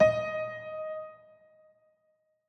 files AfterStory/Doki Doki Literature Club/game/mod_assets/sounds/piano_keys
D5sh.ogg